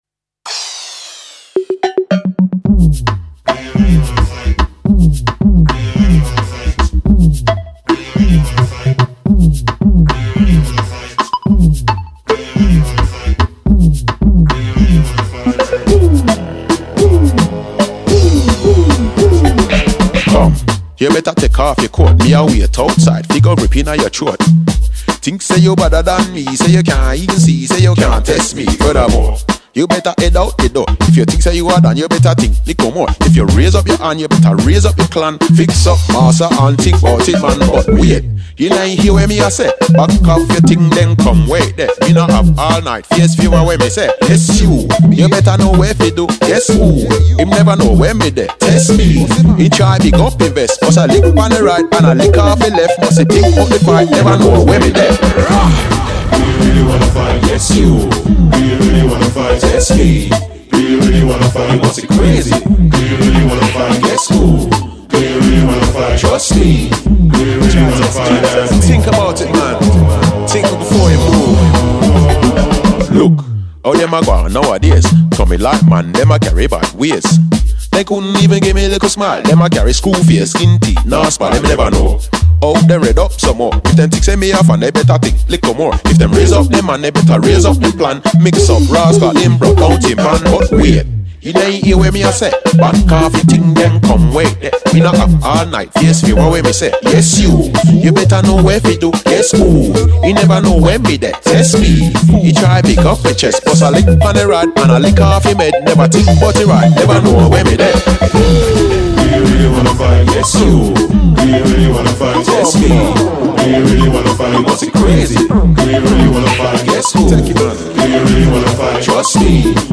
Cue the electro riffage, obviously.